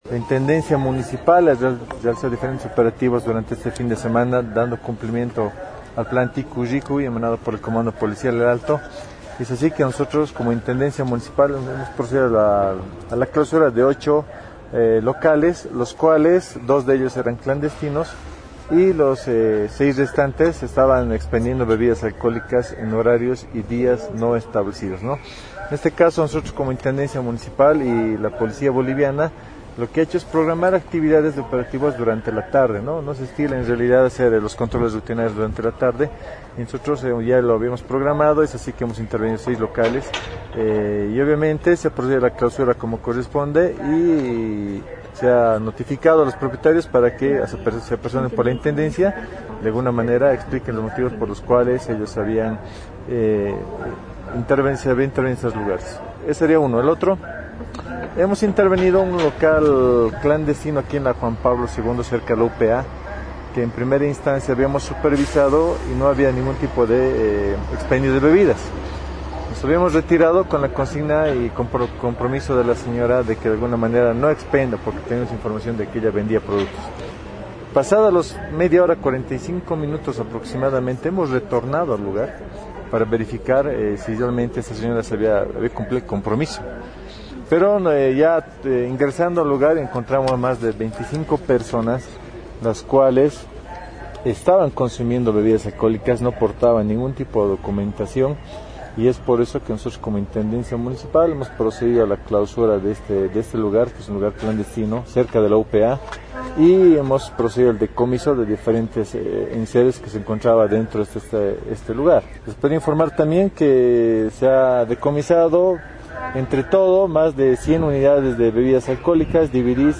05-Capitan-Ruddy-Saavedra-intendente-Mpal.mp3